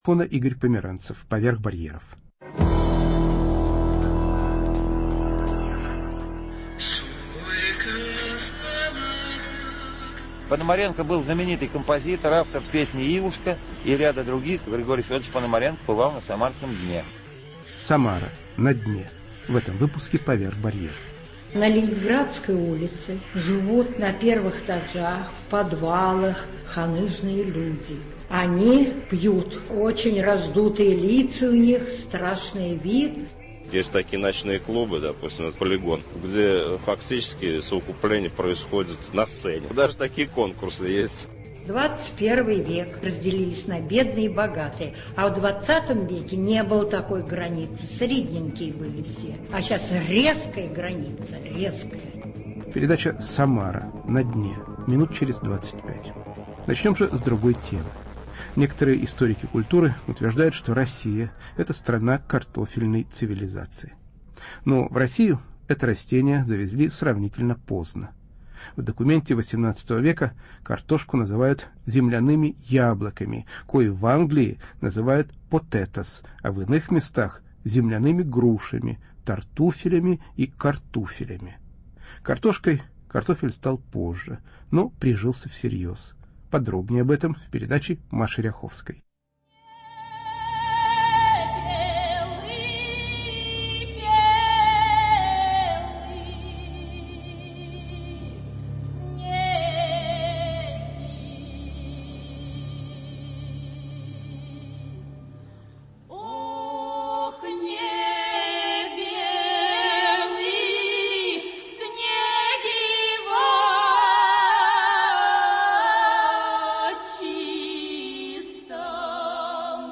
Какую роль играл и играет картофель в российской истории и в современной России? Передача с участием экспертов, крестьян, дачников.